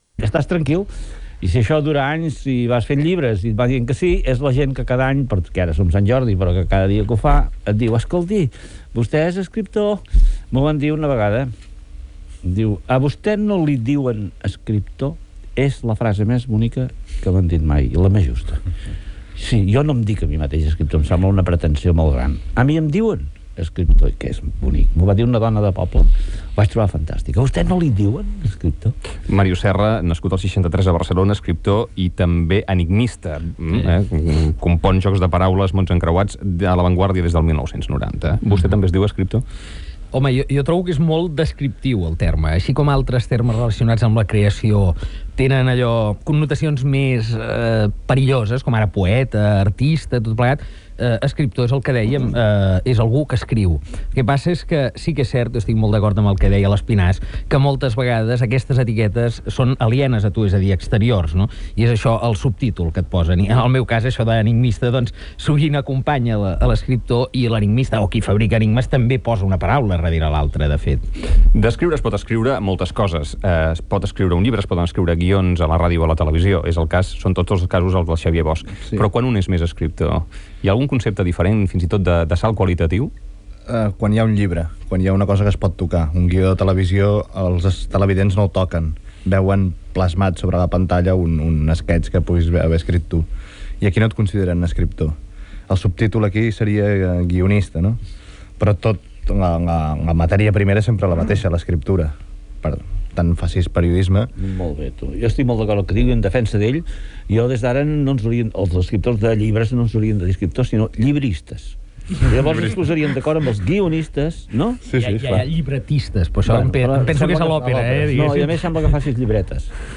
Conversa amb els escriptors Josep Maria Espinàs, Màrius Serra i Xavier Bosch
ambient de la Diada de Sant Jordi a Girona, indicatiu del programa, publicitat